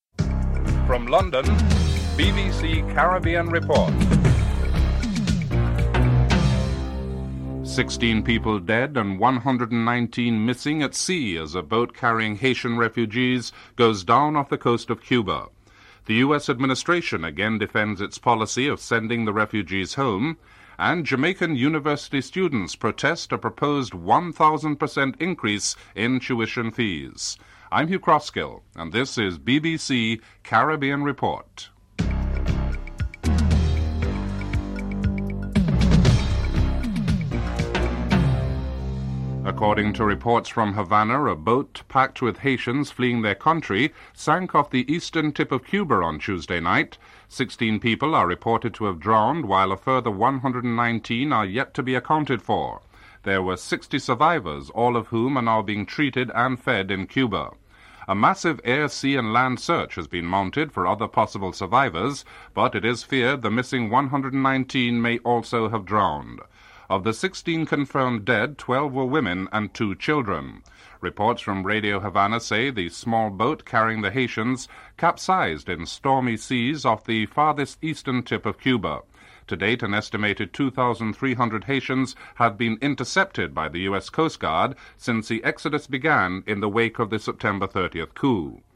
1. Headlines (00:00-00:40)
3. US administration defends its policy of sending the Haitian refugees home and states that the fleeing Haitians are economic refugees and not political refugees. Comments from Margaret Tutwiler of the State Department (04:33-05:31)